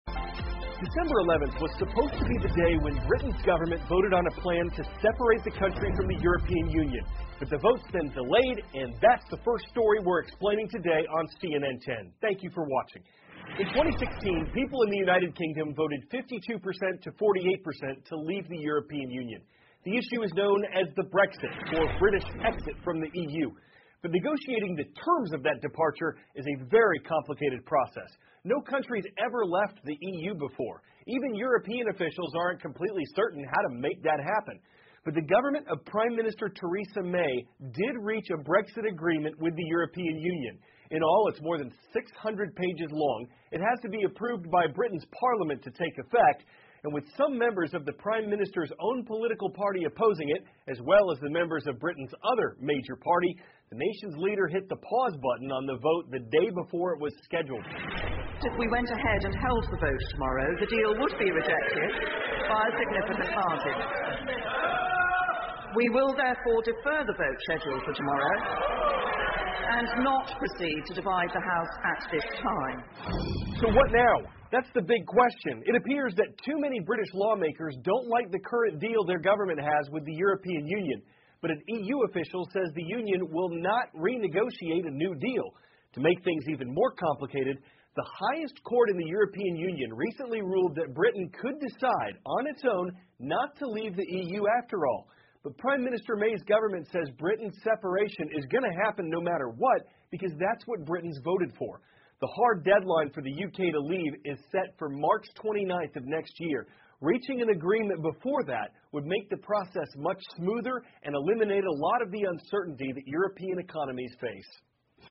美国有线新闻 CNN 英国首相梅推迟脱欧协议投票 欧盟法院裁定英国可单方面撤回脱欧决定 听力文件下载—在线英语听力室